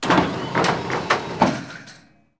st_rollerdoor.wav